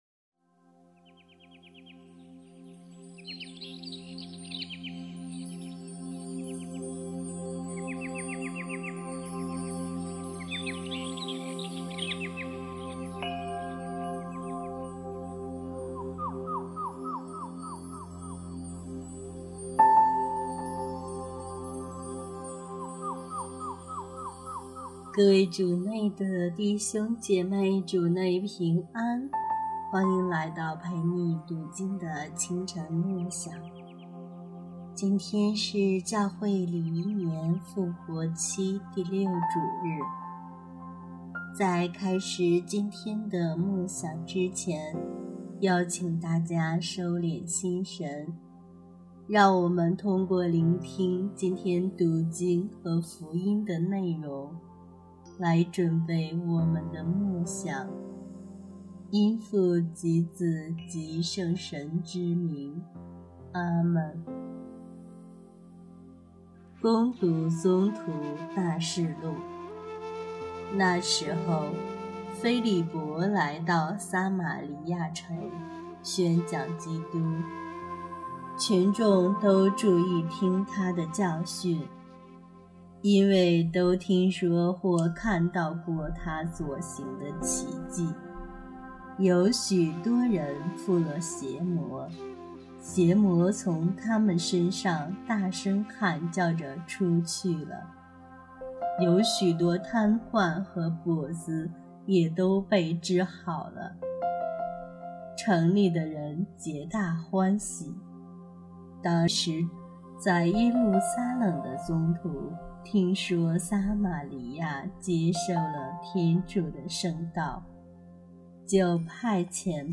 声音又成了“催眠曲了”……